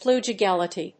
pluggability.mp3